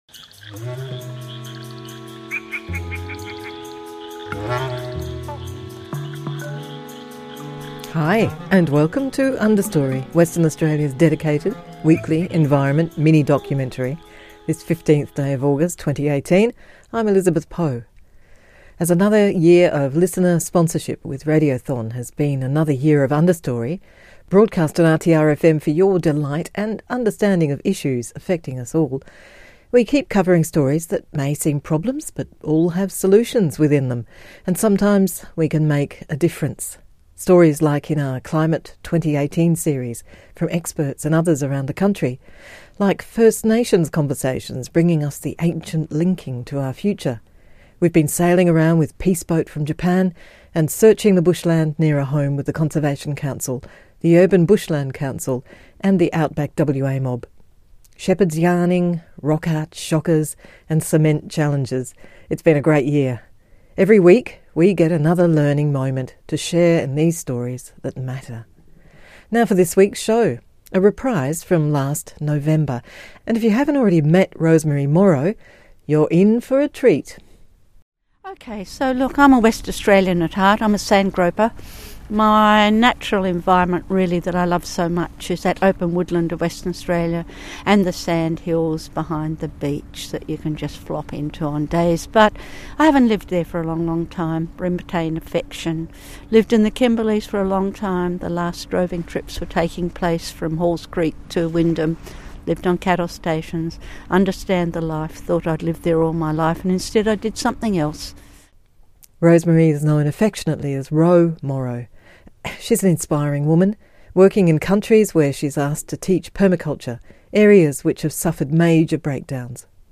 (Another version of this Understorey interview was broadcast in November 2017) Shepherds and Sustainability v2